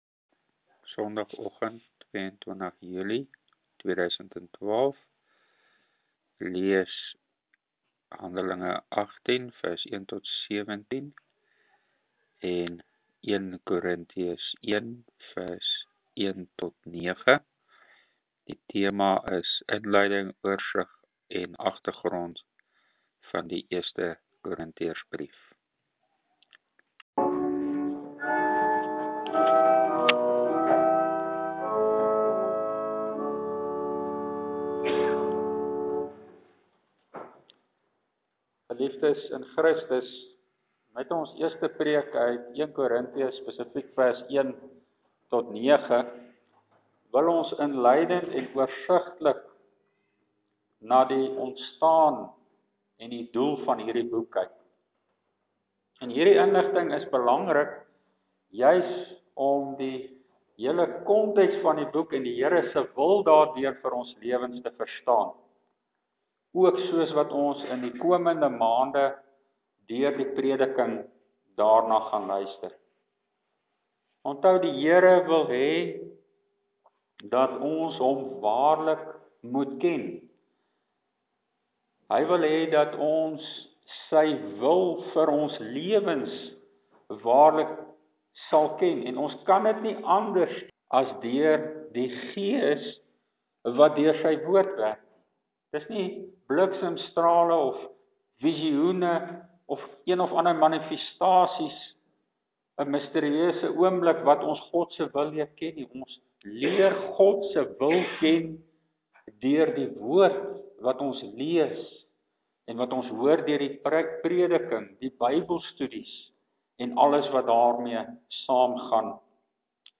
Preke uit 1 Korinthiërs: 1 Kor.1:1-9 – God is getrou